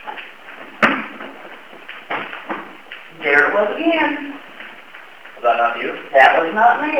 Stomp #2
A little while later, another louder stomp on the ground occurred.
stomp2.wav